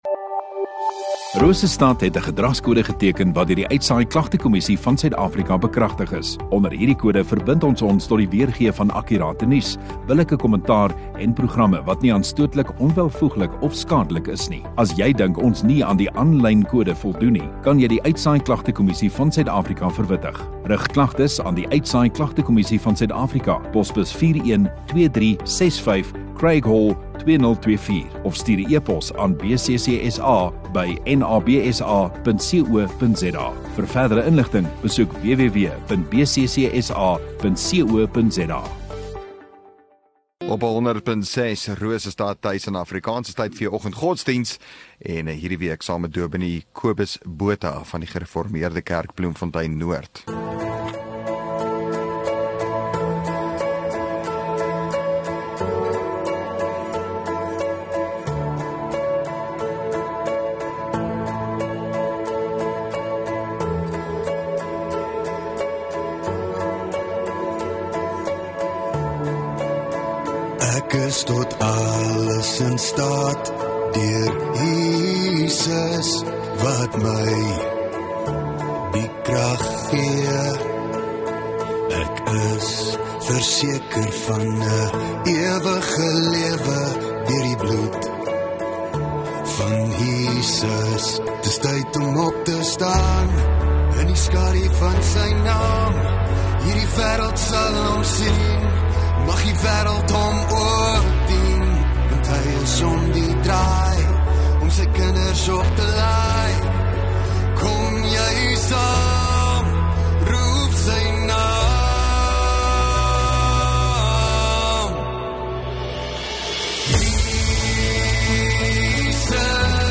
16 May Vrydag Oggenddiens